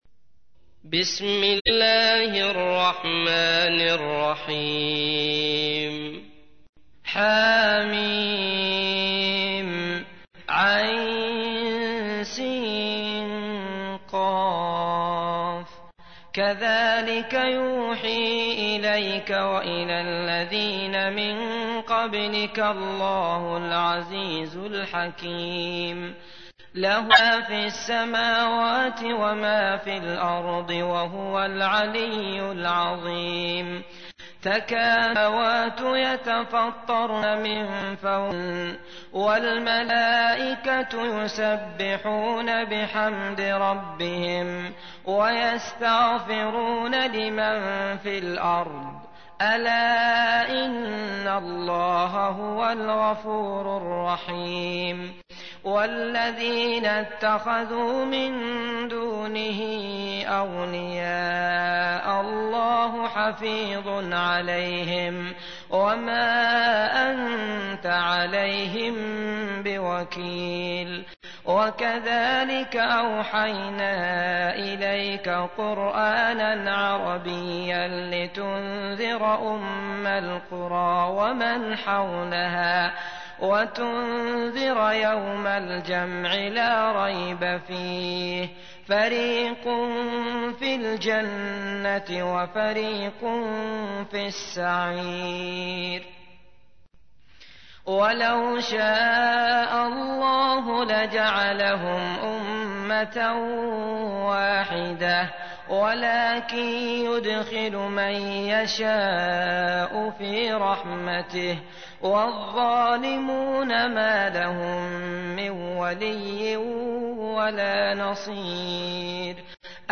تحميل : 42. سورة الشورى / القارئ عبد الله المطرود / القرآن الكريم / موقع يا حسين